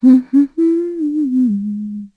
Seria-vox-Hum_kr.wav